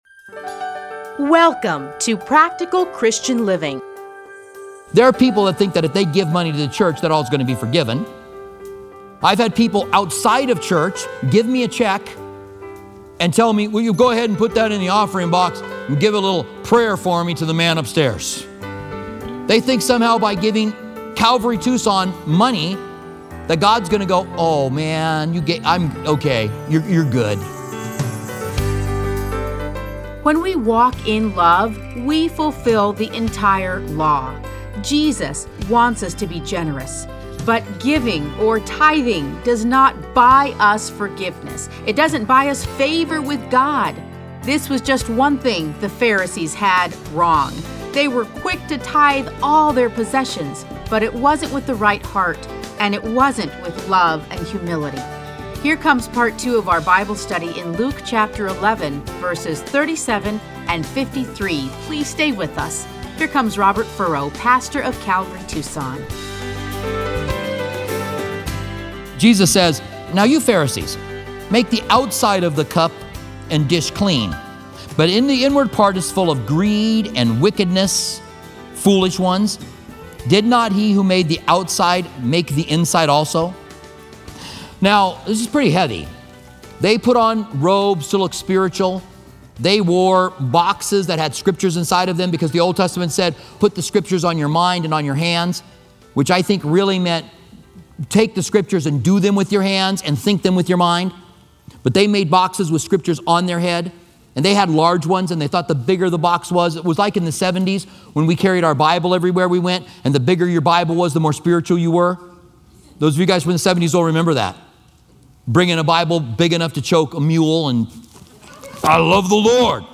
Listen to a teaching from Luke 11:37-53.